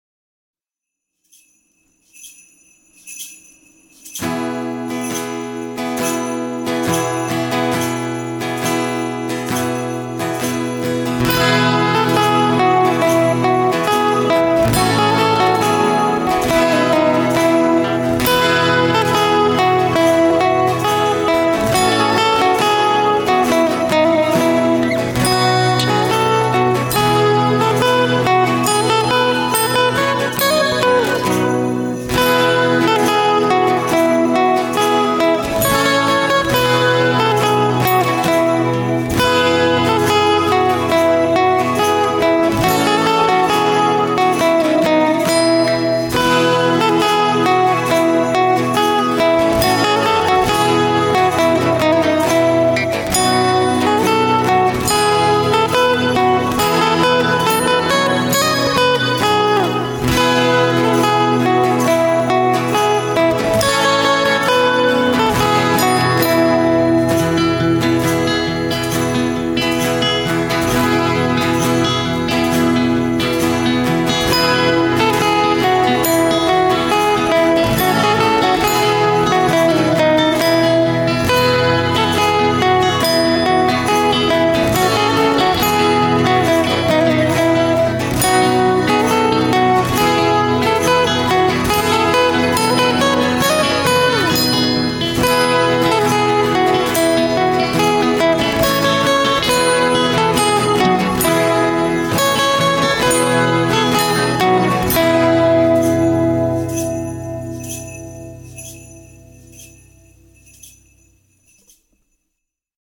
Out of my Studio in Charlotte, NC